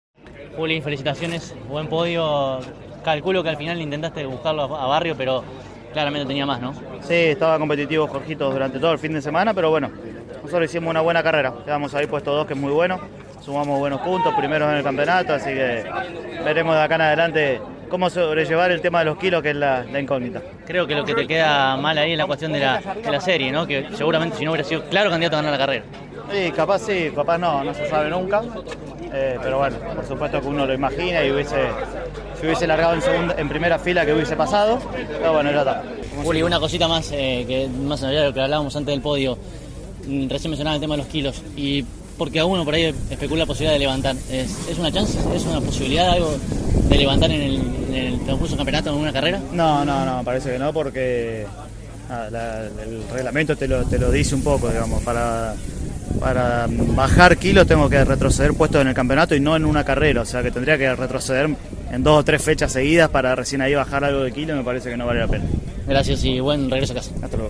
En una nueva cobertura de una competencia del TN de este medio, tanto los tres mejores de la final de la divisional mayor, como así también el mejor cordobés posicionado, fueron aquí entrevistados.